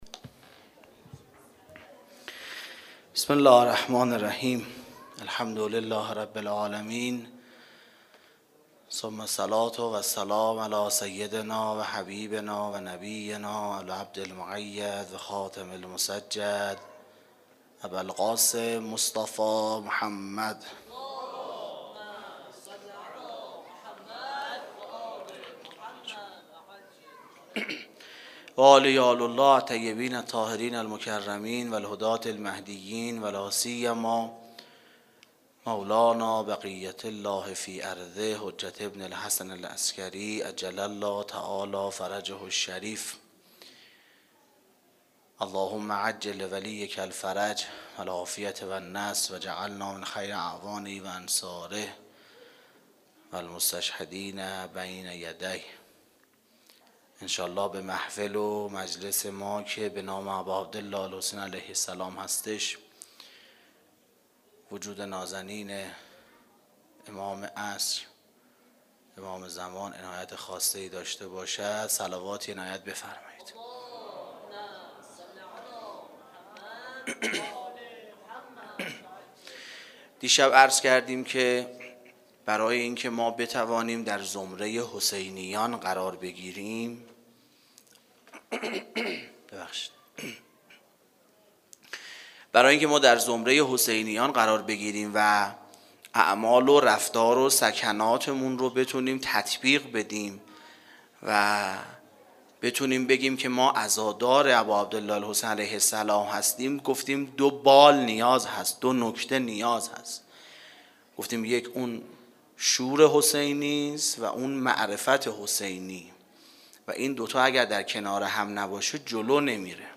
سخنرانی شب هشتم محرم
Sokhanrani-Shabe-08-moharram94.mp3